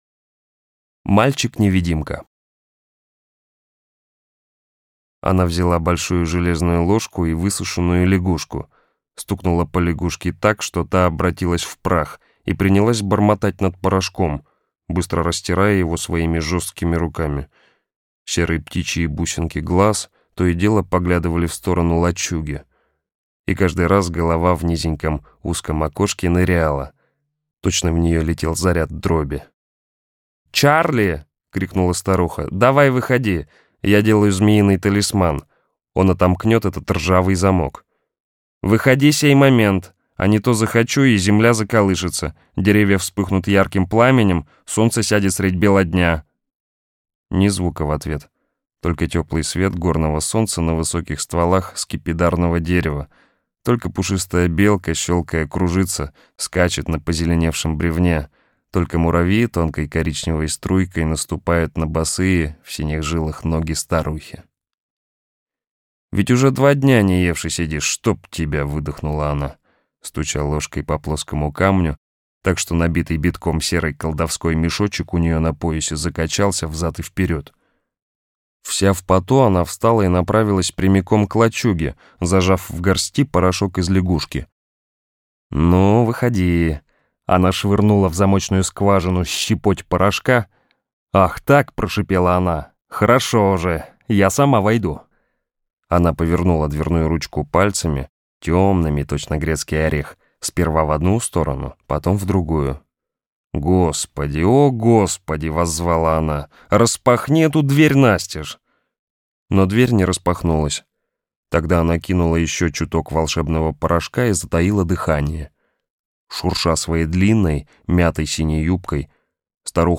Мальчик-невидимка - аудио рассказ Брэдбери Р. Рассказ про мальчика Чарли, родители которого уехали.